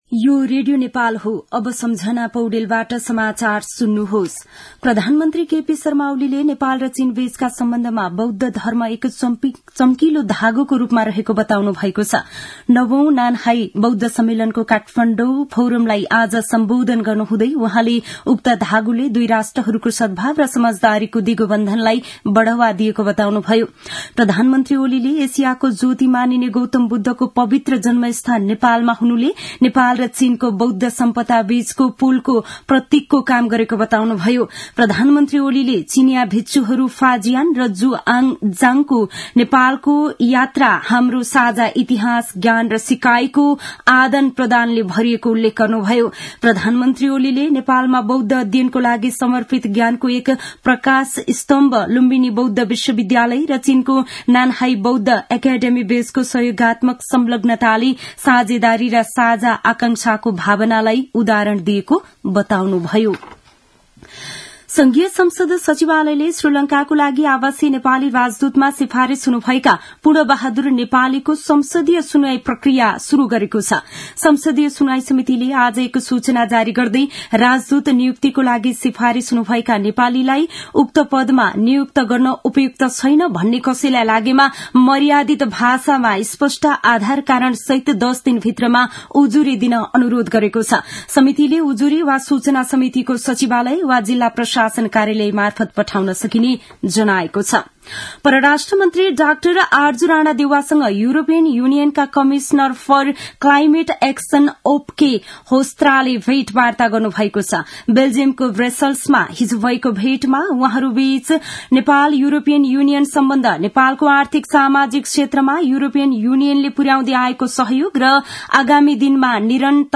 मध्यान्ह १२ बजेको नेपाली समाचार : २९ मंसिर , २०८१
12-am-nepali-news-1-11.mp3